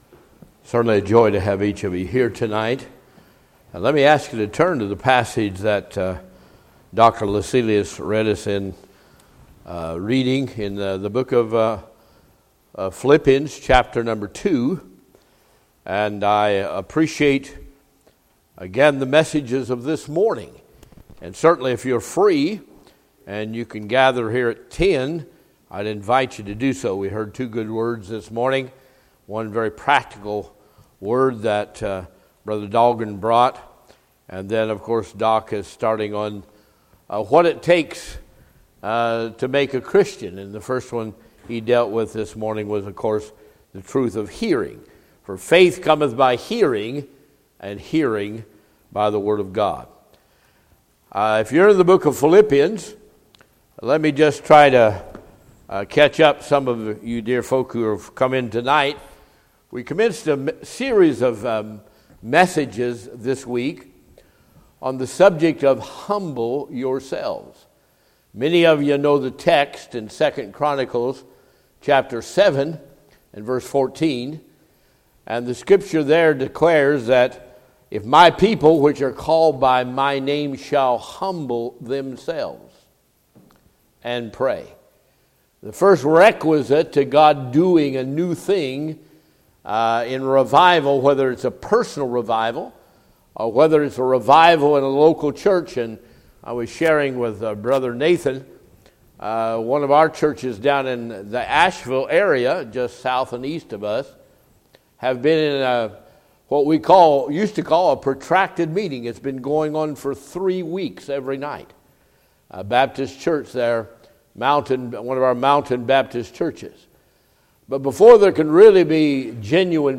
Session: Evening Session